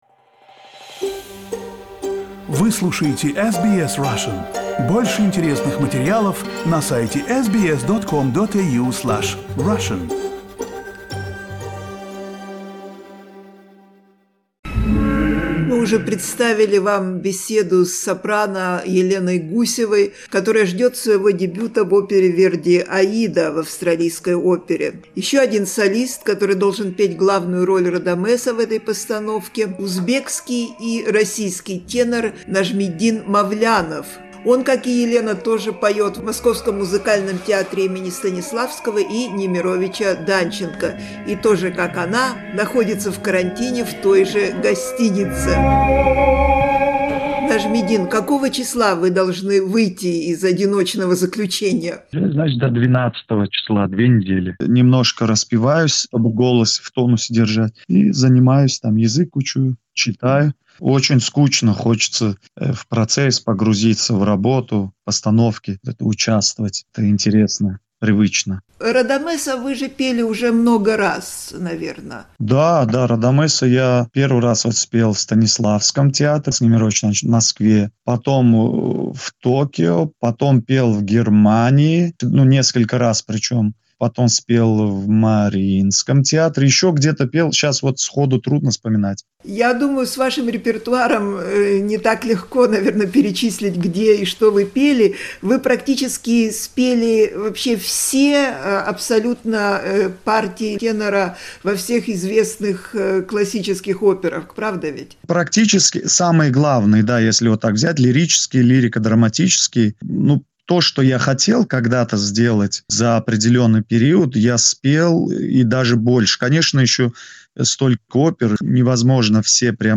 Мы побеседовали с приглашенным в Австралию солистом Музыкального театра им. К.С. Станиславского и Вл.И. Немировича-Данченко Нажмиддином Мавляновым, который коротает время в карантинном отеле, надеясь на хорошие новости.
В подкасте использованы фрагменты из арии Радамеса из оперы Верди "Аида", запись со спектакля.